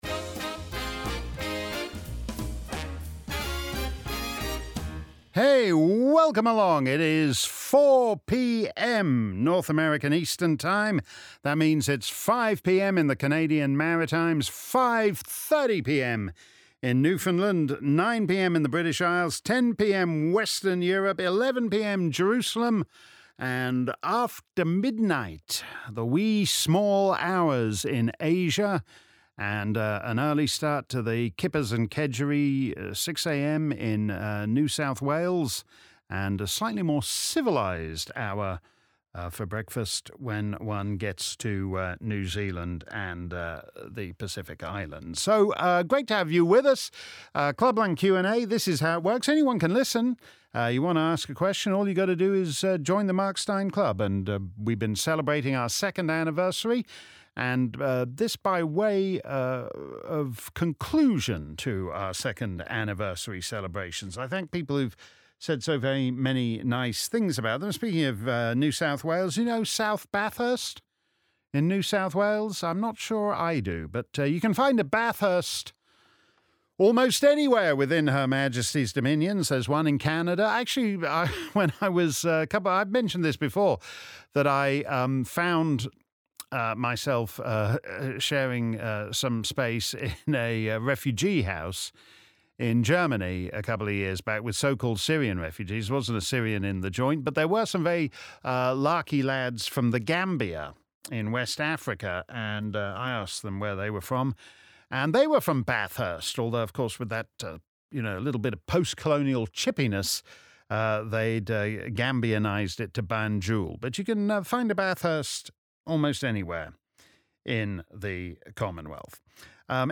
If you missed our livestream Clubland Q&A on Wednesday afternoon, here's the action replay. Simply click above and settle back for an hour of my answers to questions from Mark Steyn Club members around the planet.